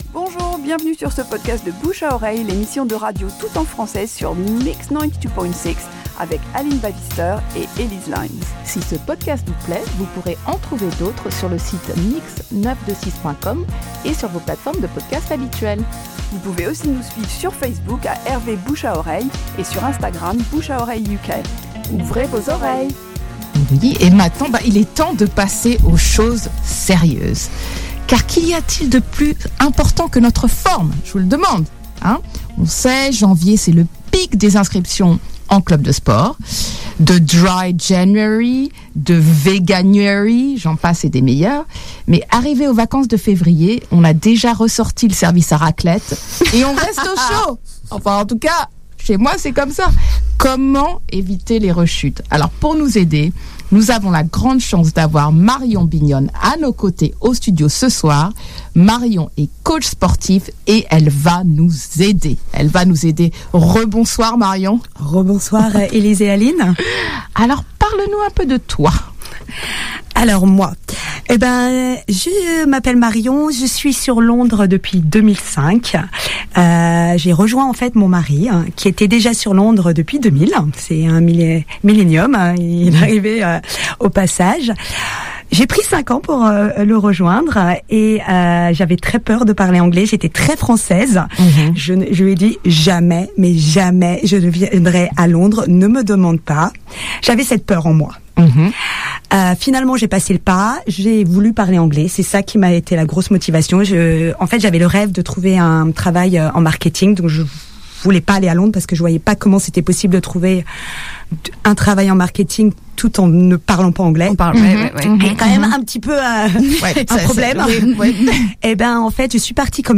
Extrait d’une émission diffusée ...